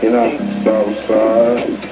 DJ SCREW INTERVIEW (OUTSIDE).wav